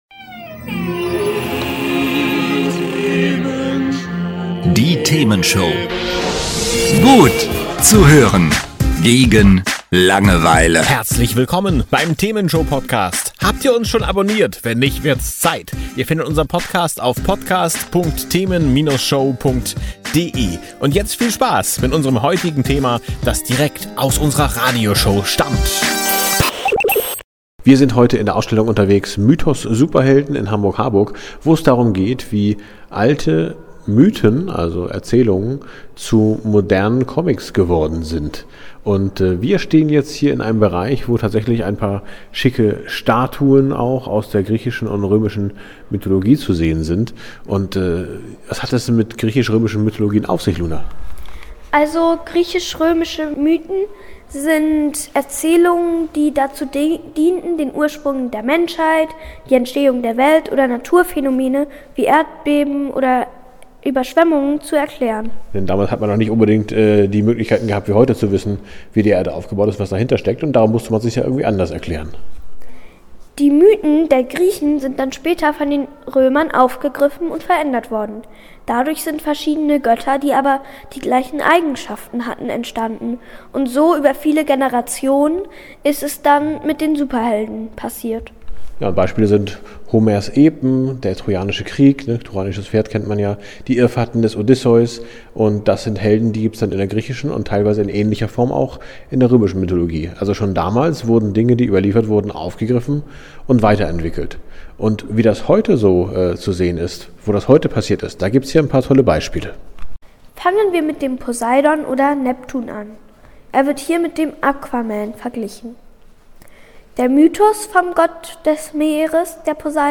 auf eine faszinierende Entdeckungsreise direkt in die Ausstellung „Mythos Superhelden“ nach Hamburg-Harburg. Wir gehen Fragen auf den Grund, die das herkömmliche Bild unserer Leinwandhelden ordentlich ins Wanken bringen: Wir blicken hinter die glänzenden Fassaden der Superkräfte: Erfahrt, was einen wahren Helden wirklich ausmacht – sind es nur die Muskeln, oder ist es die unerschütterliche Willenskraft?